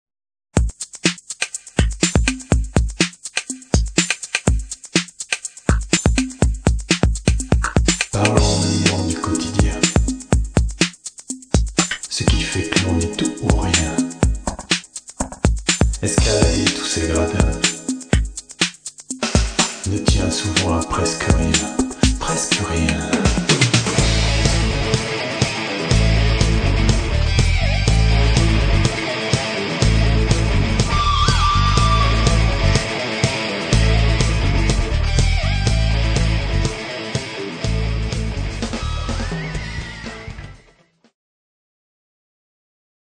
Groupe Rock PAU